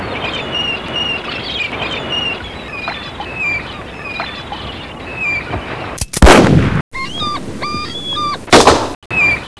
A man shooting the seaguls